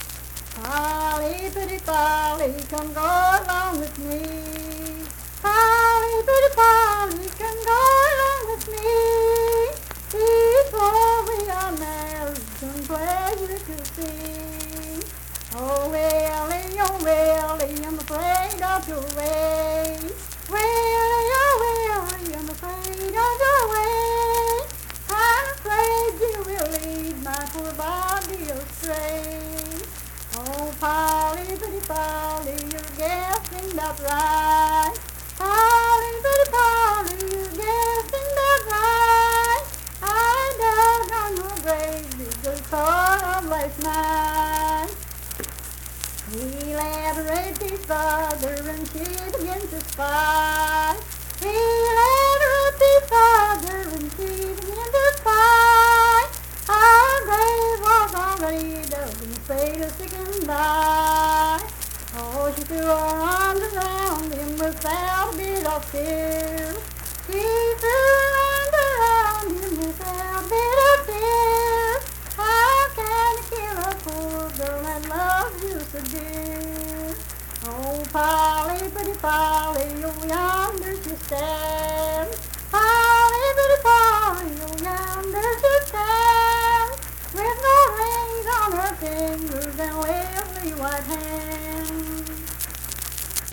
Unaccompanied vocal music
Verse-refrain, 6(3).
Voice (sung)